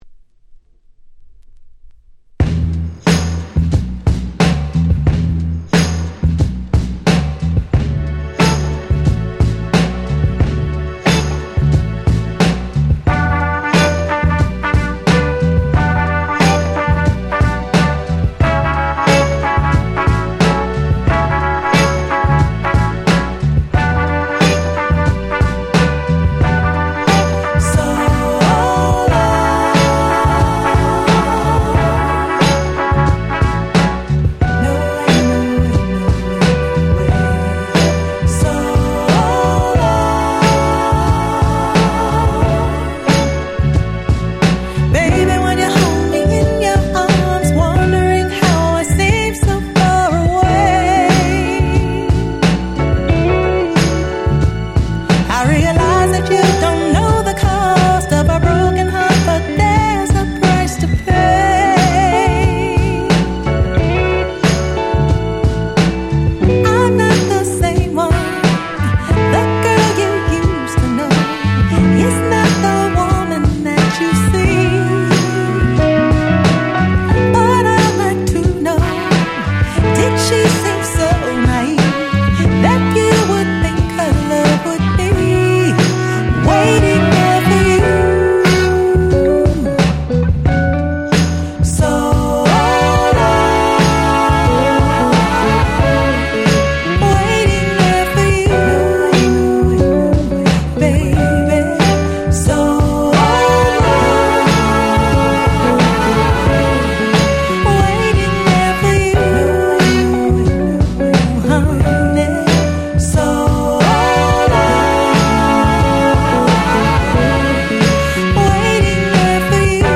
10' Super Nice Neo Soul / R&B !!